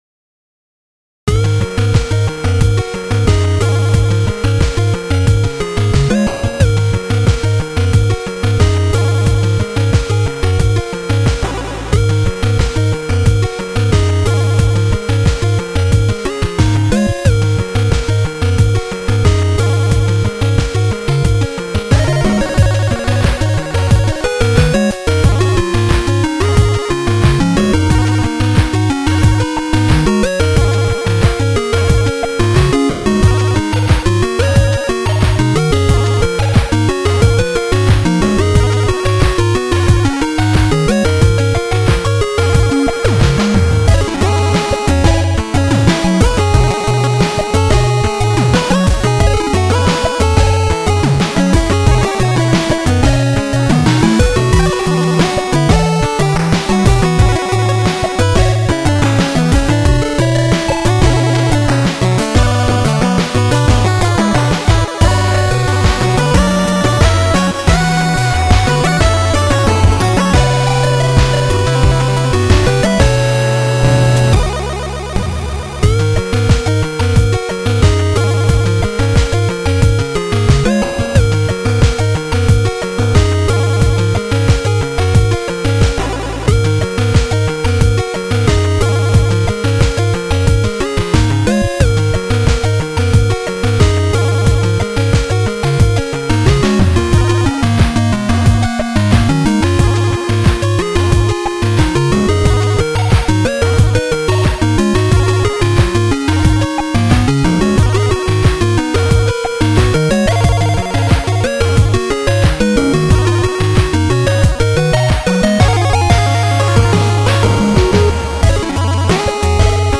NSF は、ファミコン演奏用のデータです。
(2A03)